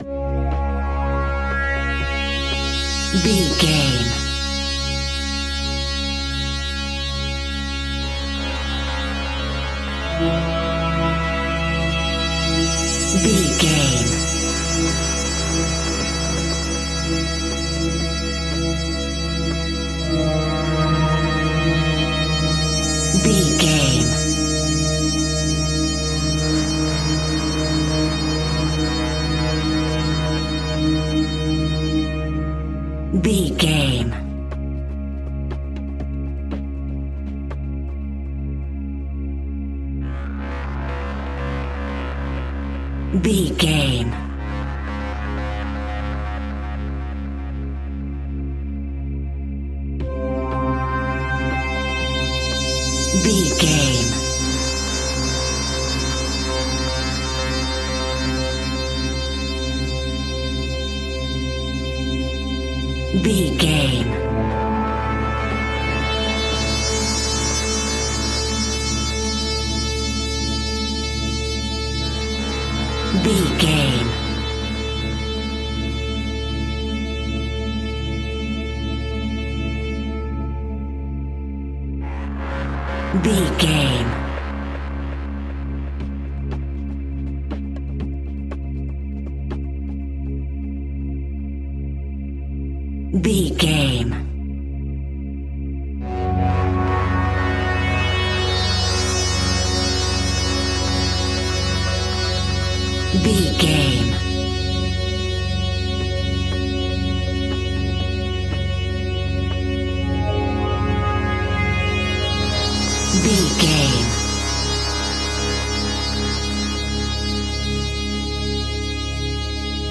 Aeolian/Minor
C#
ominous
dark
eerie
drums
synthesiser
creepy
ambience
pads
eletronic